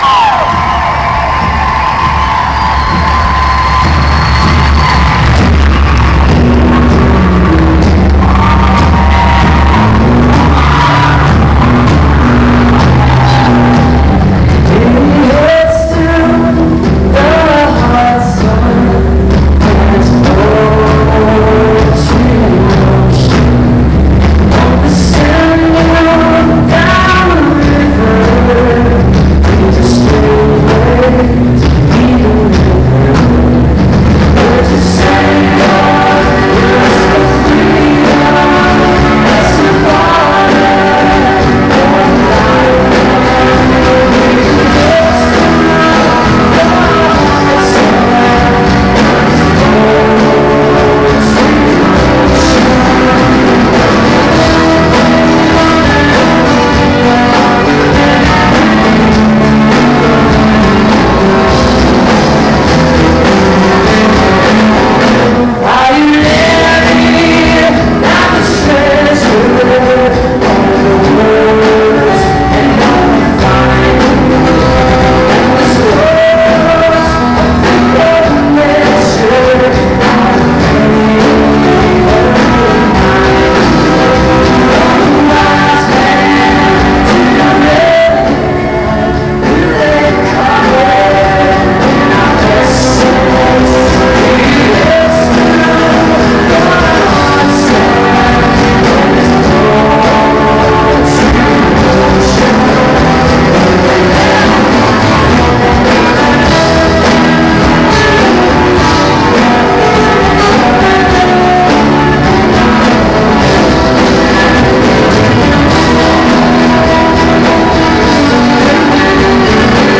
(this recording has some distortion)